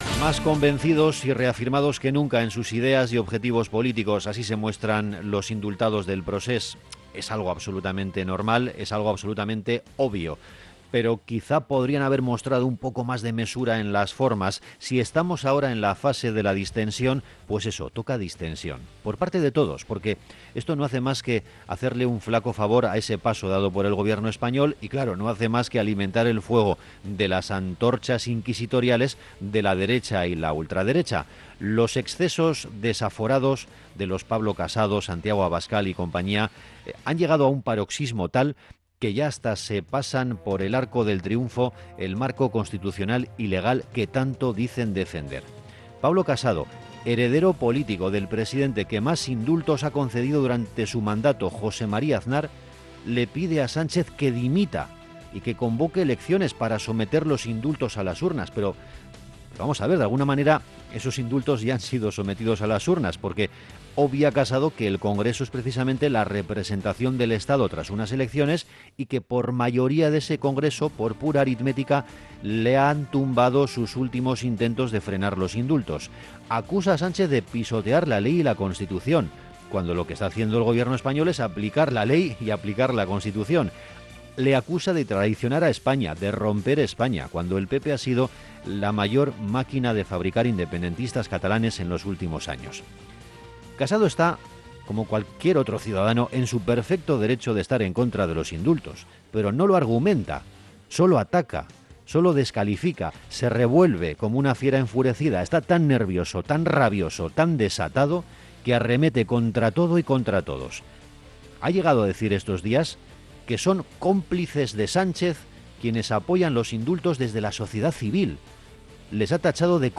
El editorial